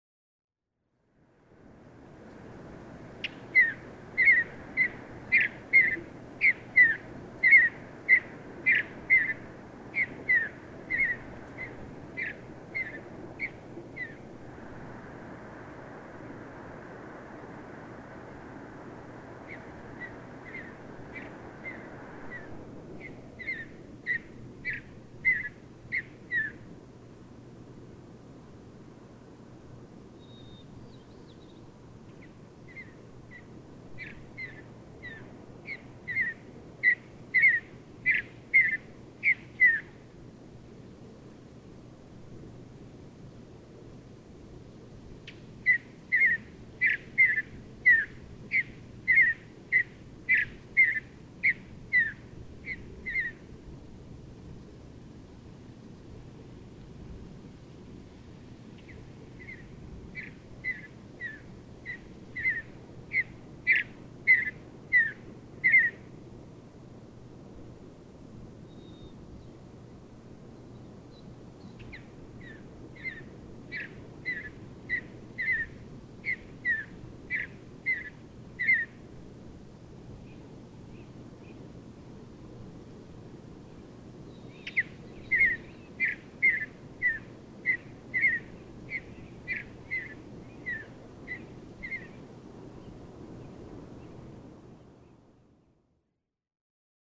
Mountain bluebird
Circling high above Old Faithful, in dawn song, well before the crowds will gather later this morning. 4:30 a.m. 50 minutes before sunrise.
Above Old Faithful, Yellowstone National Park, Wyoming.
544_Mountain_Bluebird.mp3